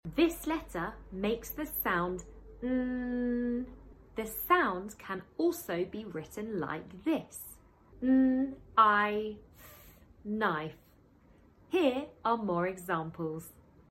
The long ‘nnnnn’ sound can also be written as ‘kn’ or ‘n’. In this video, practice reading words with ‘kn’ making the long ‘nnnn’ sound.